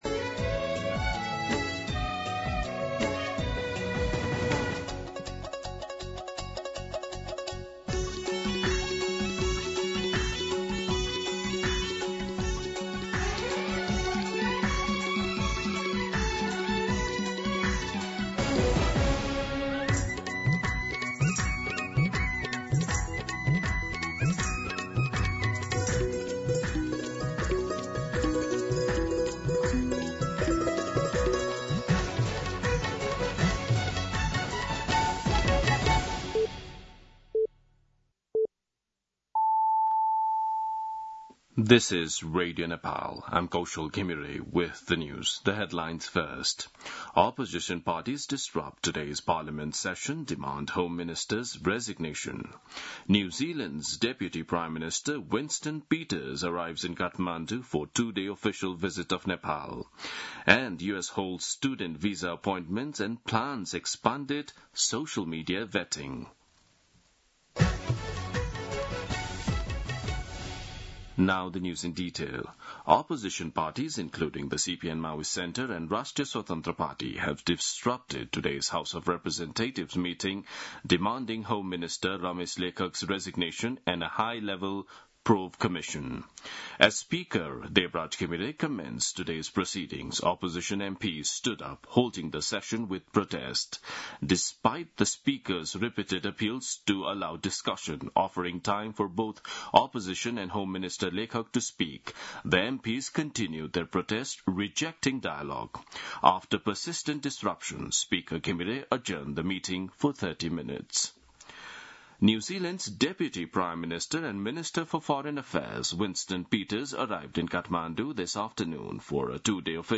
दिउँसो २ बजेको अङ्ग्रेजी समाचार : १४ जेठ , २०८२
2pm-English-News-14.mp3